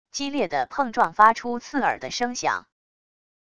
激烈的碰撞发出刺耳的声响wav音频